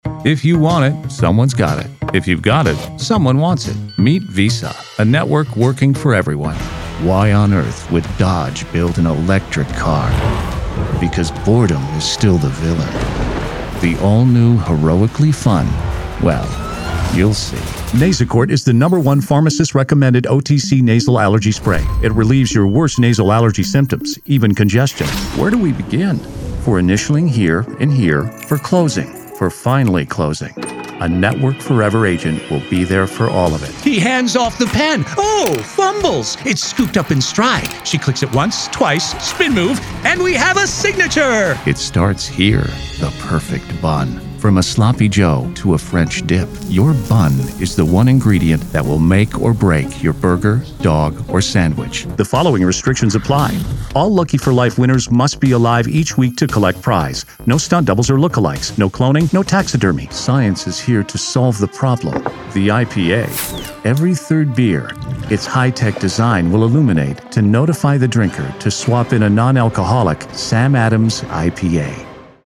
DEMO
businessman relatable / acerbic / sensibility / highly relatable / ironic commentary.